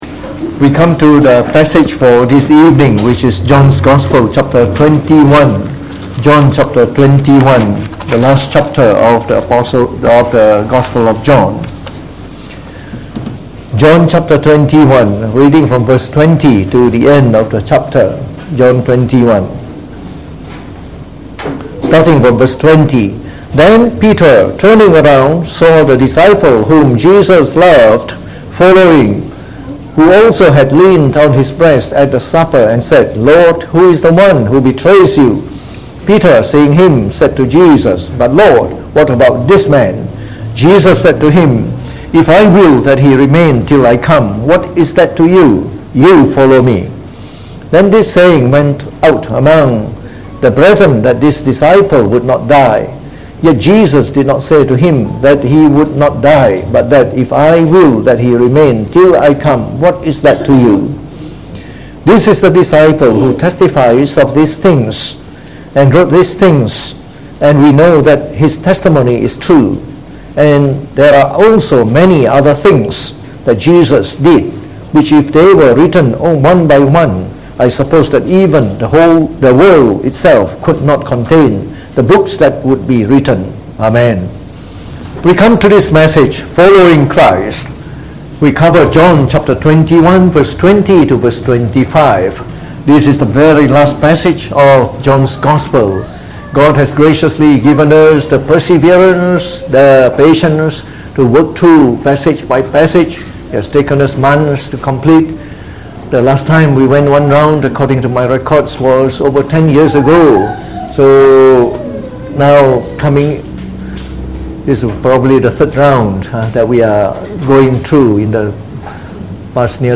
Preached on the 25th of Aug 2019.